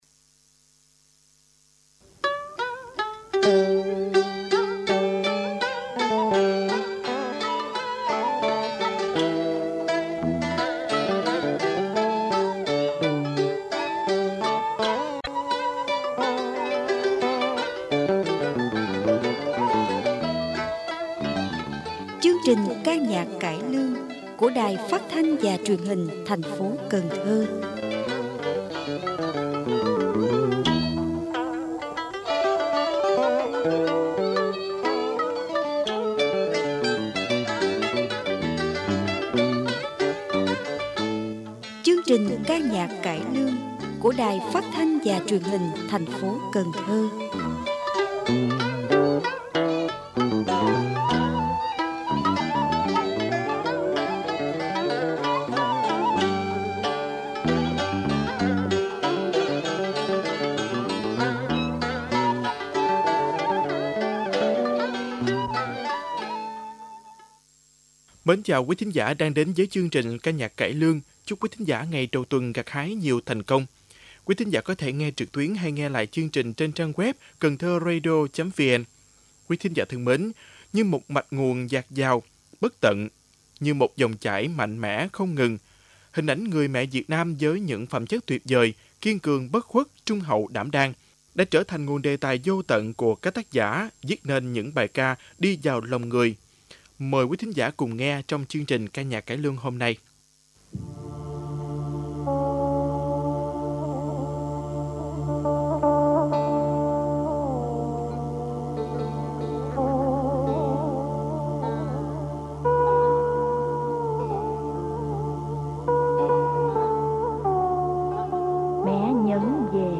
Ca nhạc cải lương: Trái tim người mẹ